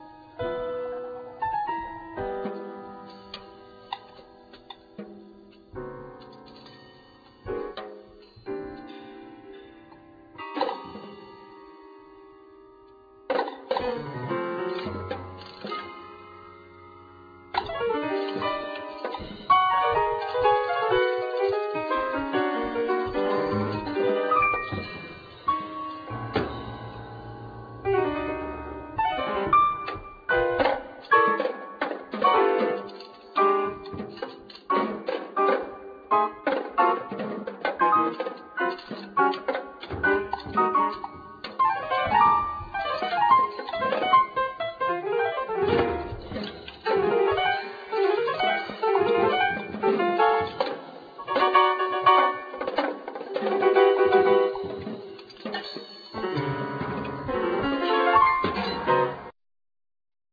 Piano, Percussion, Prepared piano
Drums, Gong